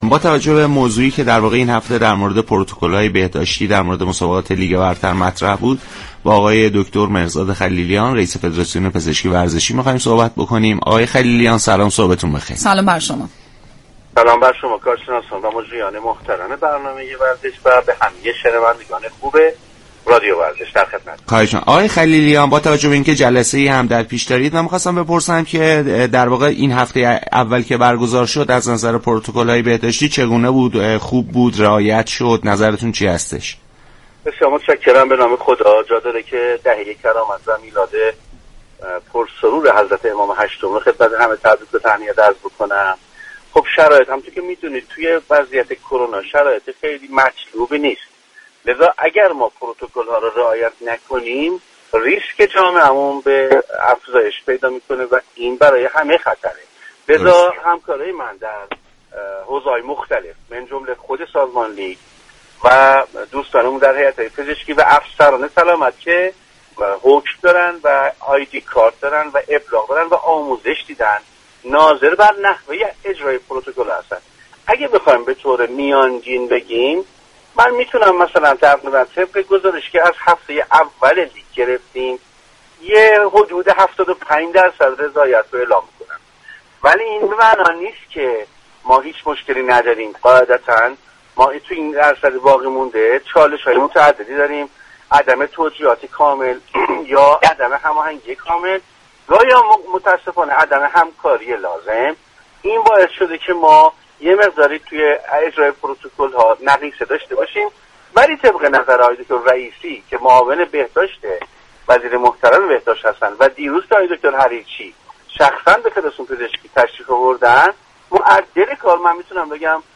دریافت فایل به گزارش رادیو ورزش؛ مهرزاد خلیلیان، رئیس فدراسیون پزشكی ورزشی، در پاسخ به این سوال كه آیا در هفته اول برگزاری لیگ پروتكل های بهداشتی رعایت شد، اظهار داشت: با توجه به شیوع كرونا، شرایط مطلوبی حاكم نیست؛ لذا اگر پروتكل ها را رعایت نكنیم، ریسك جامعه افزایش پیدا می كند و این اتفاق برای همه خطرناك است.